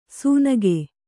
♪ sūnage